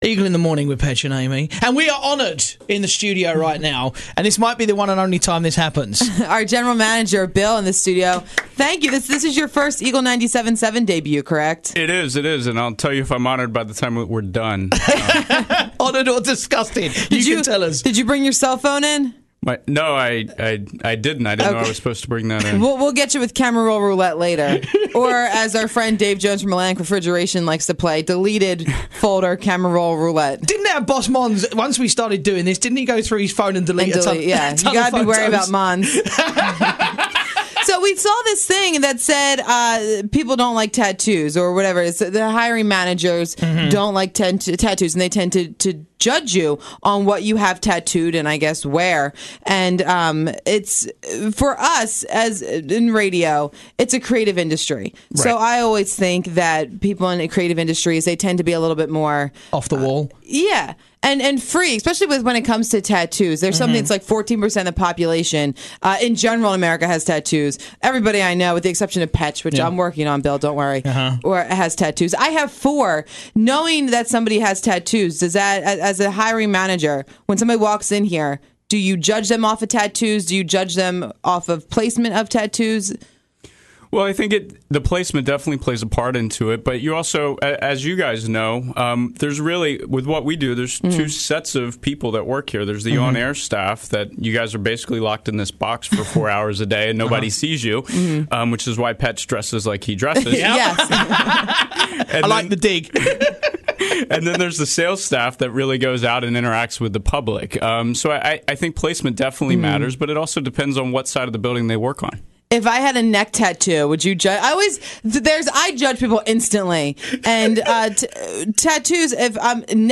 come into the studio to talk about Hiring someone with Tattoo's